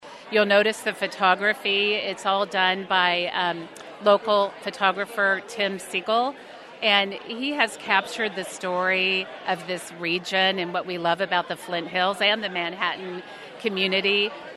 Manhattan celebrated Stormont Vail Health’s new campus with a ribbon cutting Friday